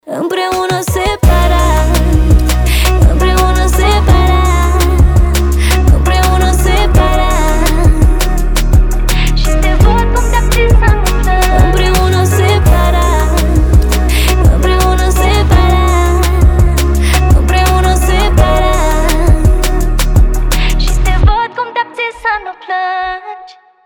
• Качество: 320, Stereo
красивый женский голос